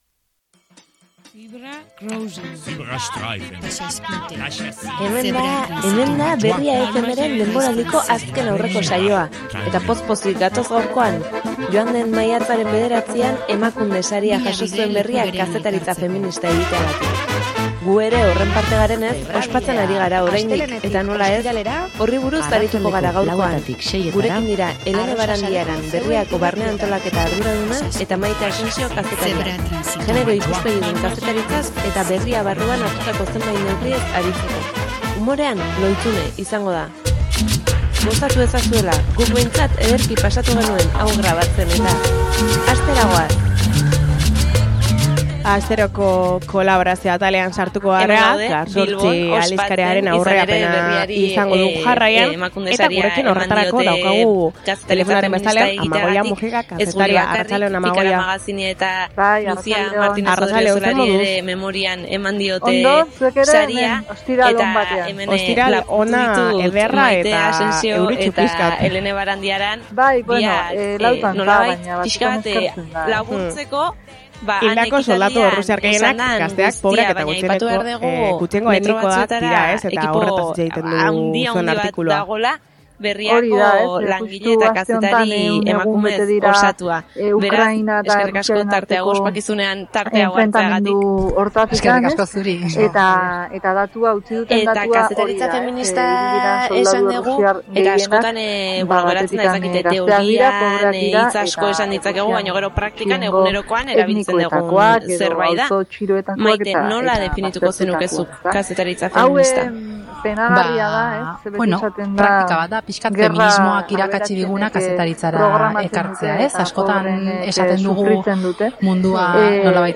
-Ukrainako gerra: Hildako soldadu errusiar gehienak gazteak, pobreak eta gutxiengo etnikoetakoak dira -Haurren jarrera pentsalaria sustatzeko saioak antolatzen ditu Jakinmin elkarteak -Elkarrizketa